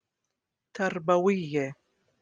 Jordanian